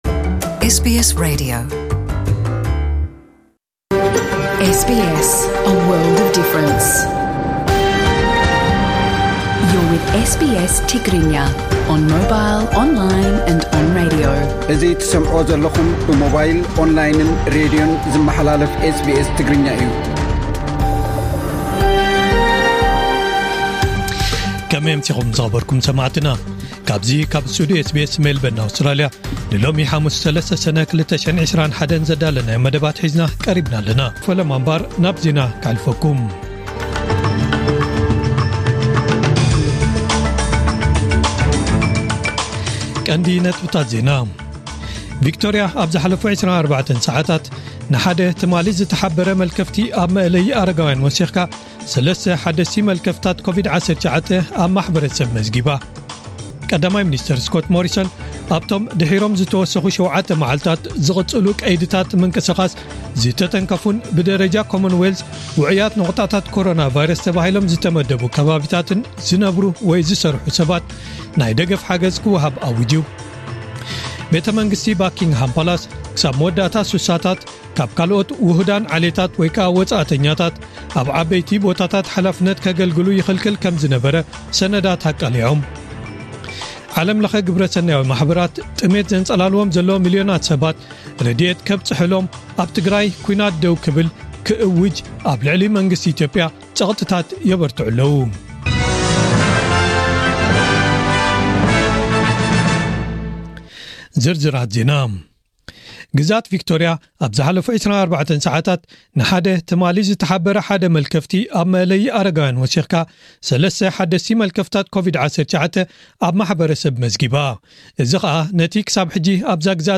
ዕለታዊ ዜና ኤስቢኤስ ትግርኛ (03/06/2021) Source: Getty